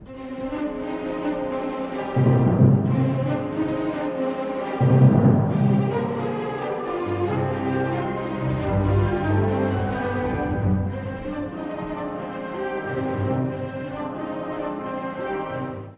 第2楽章｜荒ぶるスケルツォから、壮麗な終結へ
前半のスケルツォでは、循環主題の変形が用いられ、再び裏拍から始まることで不安定さが際立ちます。
その中間部には、明るいC-durのプレストが差し込まれ、音楽に一筋の光が射すような感覚が味わえます。
突如、オルガンの強奏ではじまるフィナーレは圧巻。